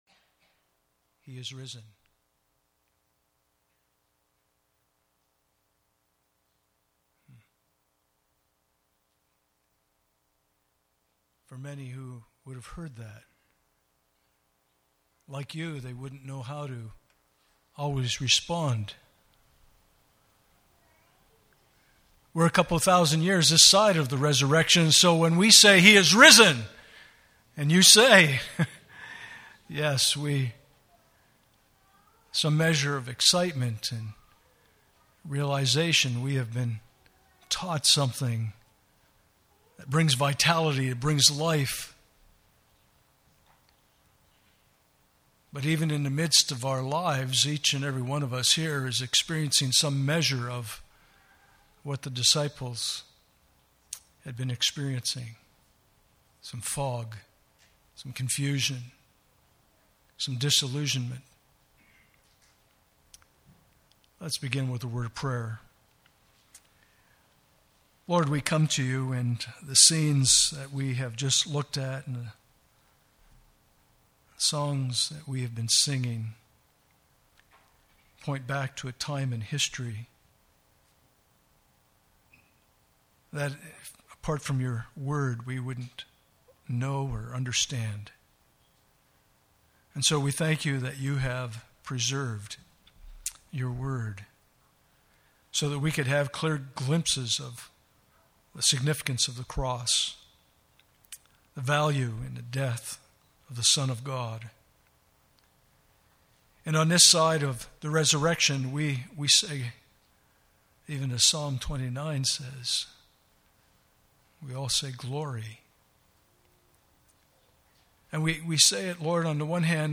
Passage: 1 Corinthians 2:6-16 Service Type: Sunday Morning